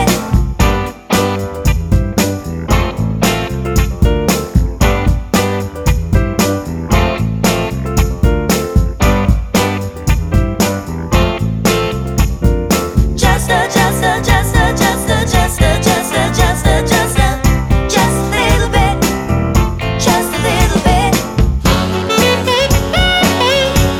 no sax solo Soul / Motown 2:28 Buy £1.50